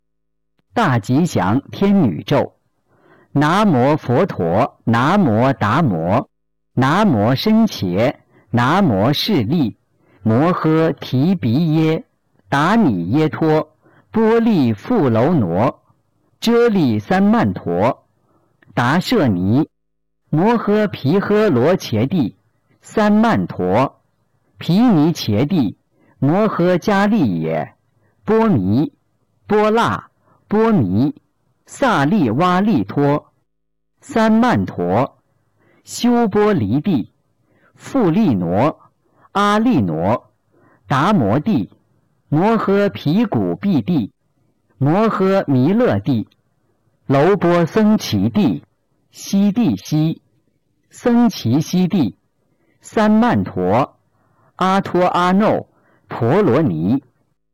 015《大吉祥天女咒》教念男声